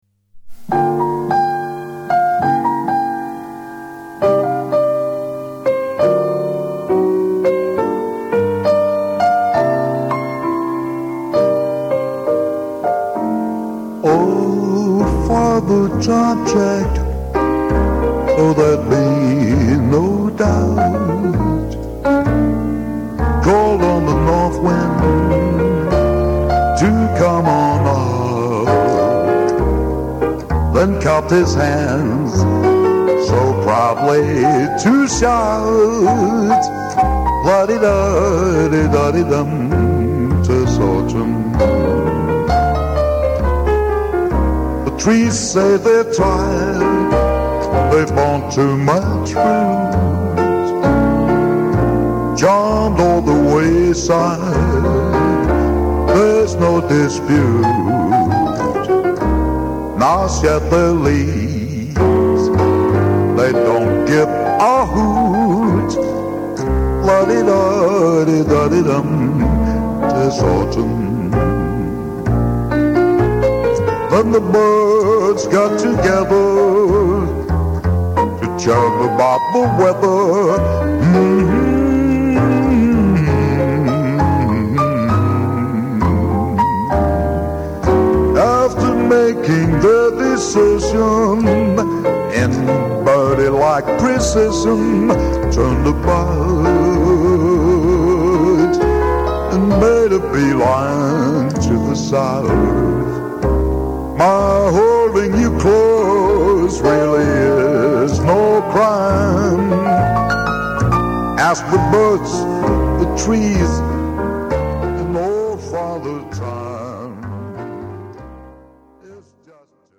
En blød, fyldig stemme.
der spiller trommer her
En god sammenspillet trio.